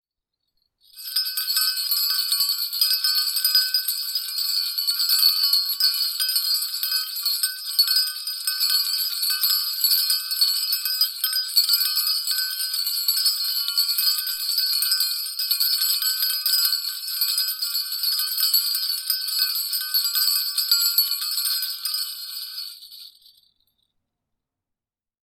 Sleigh Bells, Long, A
bell Bell bells Bells ding Ding jangle Jangle sound effect free sound royalty free Sound Effects